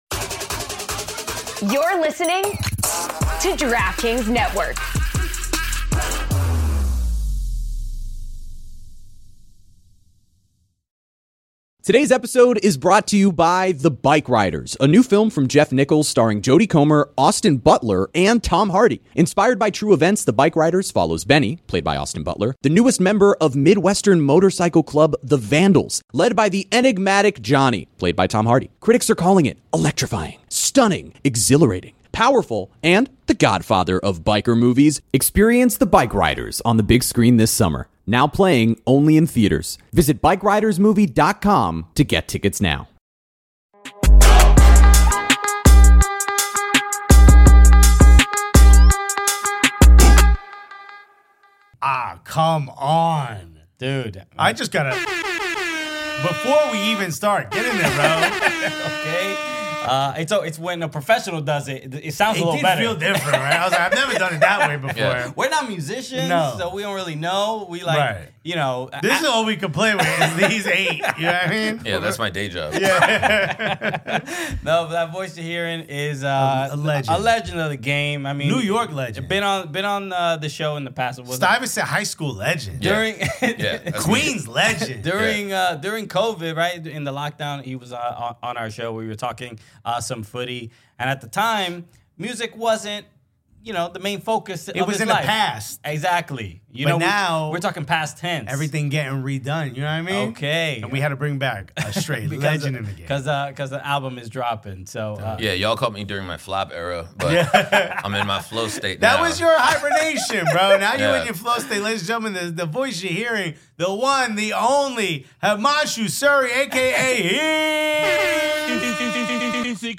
Don't miss out on the hilarious interview!